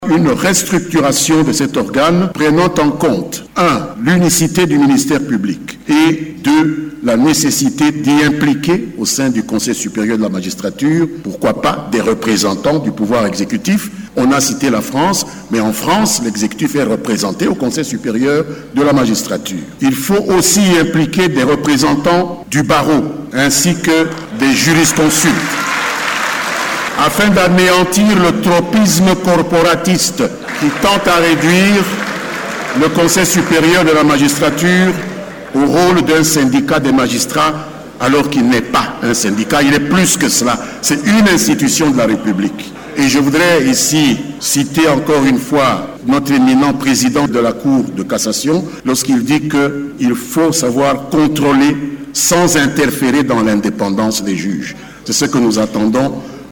Allant dans le même sens, le député national Lambert Mende a exposé sur l’évolution du système judiciaire congolais : reformes, défis et perspectives.